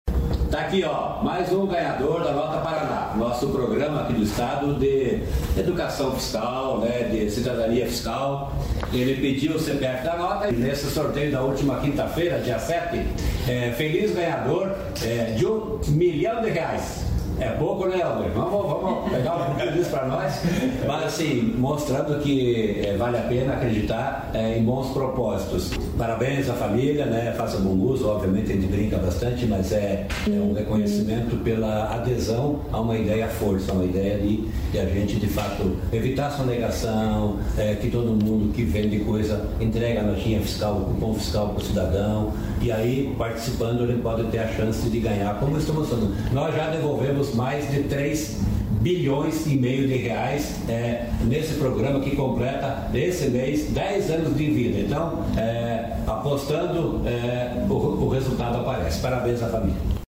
Sonora do secretário da Fazenda, Norberto Ortigara, sobre o prêmio de um milhão de reais do Nota Paraná